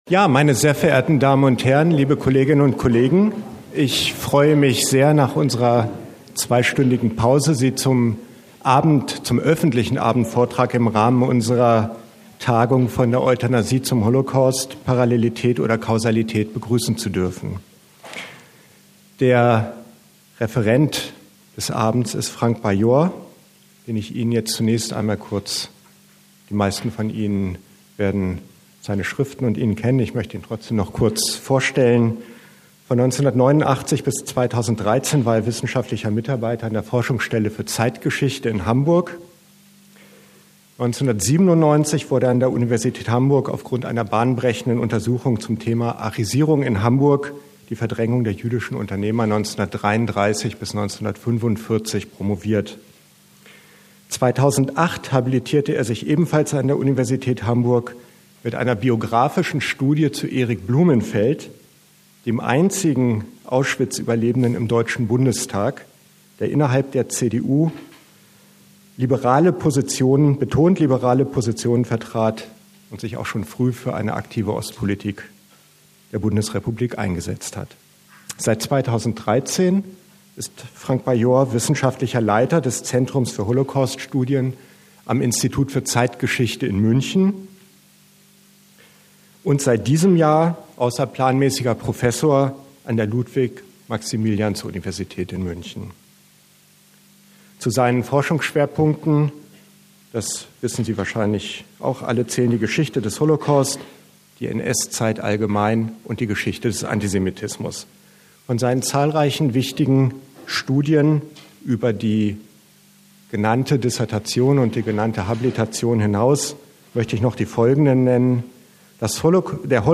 Donnerstag, 24. November 2016 in der Goethe-Universität Frankfurt am Main